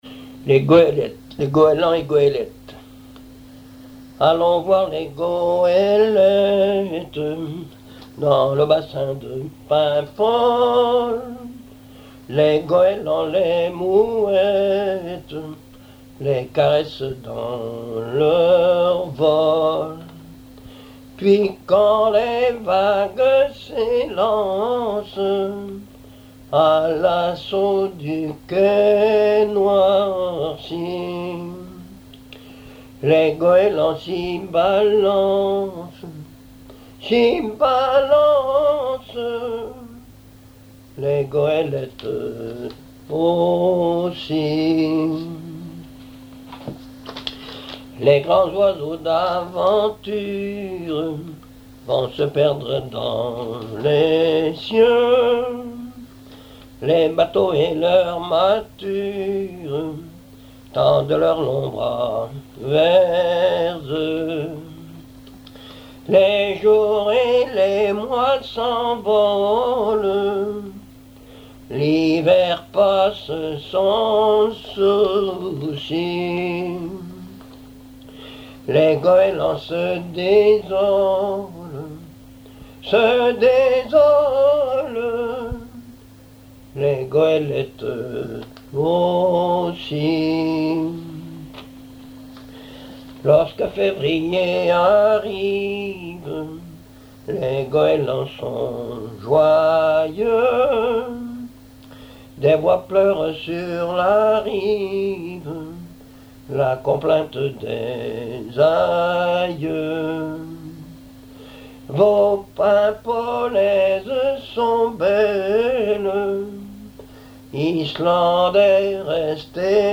Genre strophique
chansons populaires et histoires drôles
Pièce musicale inédite